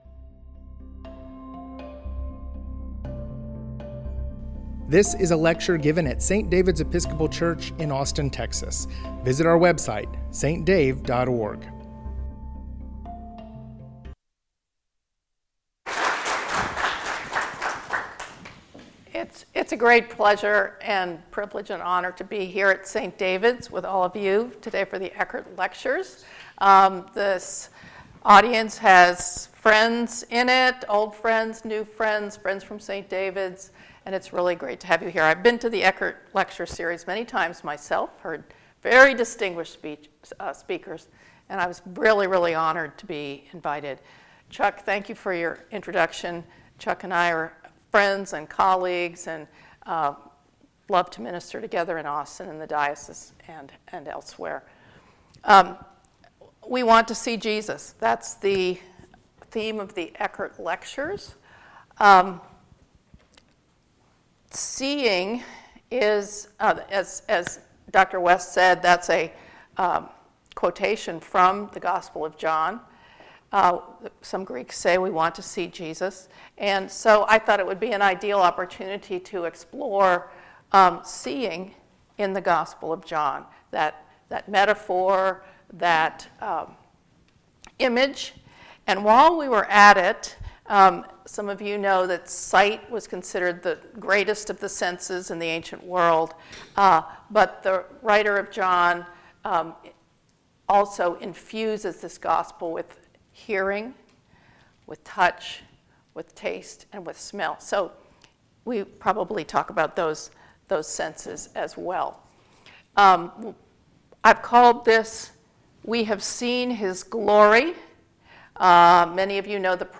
Lecture I